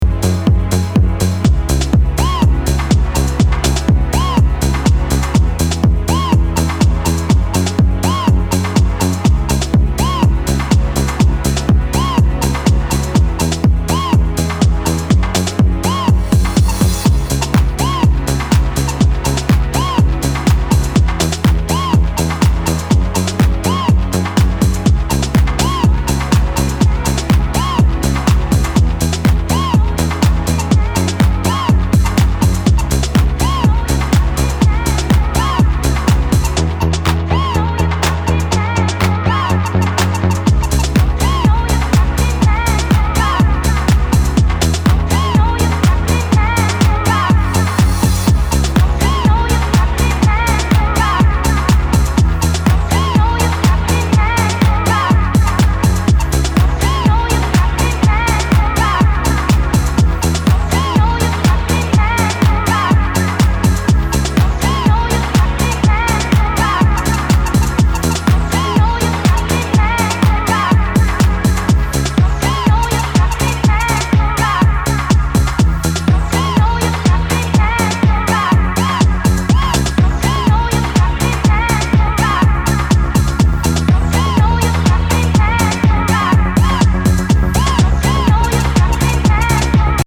trio of heartfelt ravey corkers